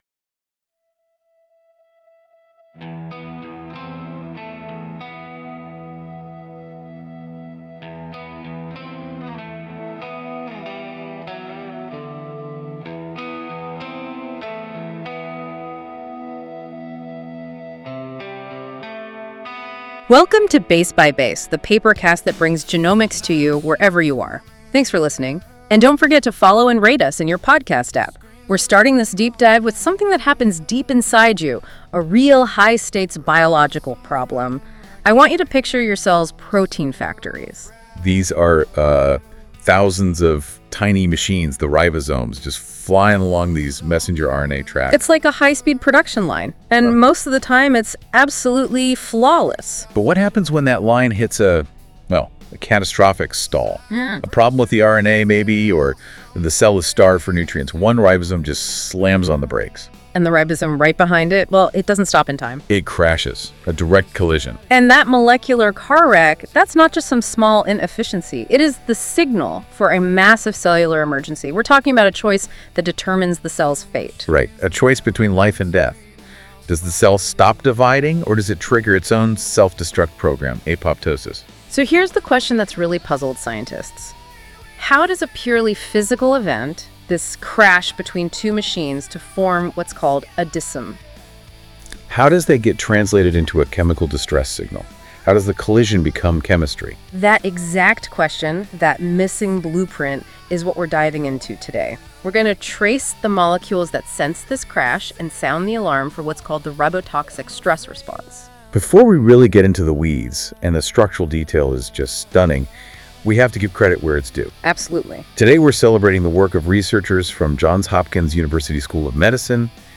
ZAK, Collided Ribosomes, and the Stress Switch Music:Enjoy the music based on this article at the end of the episode.